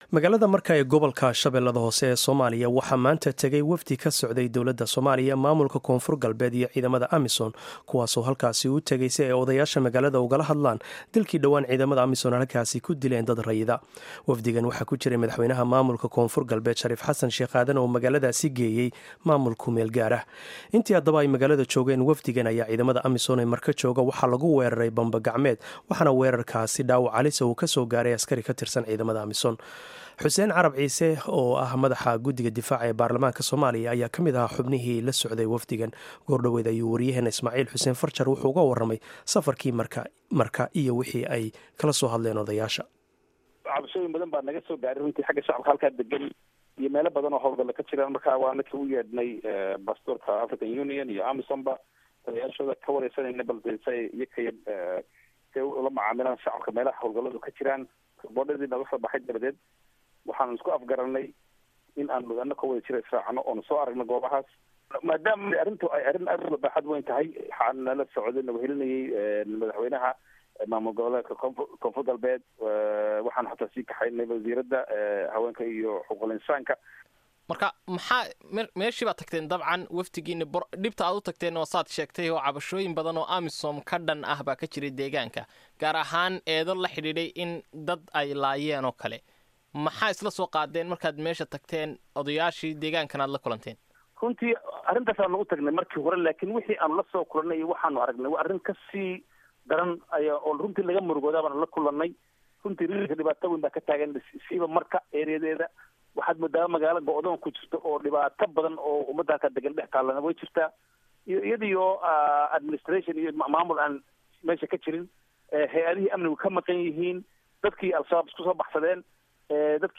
Waraysiga Xildhibaan Xuseen Carab Ciise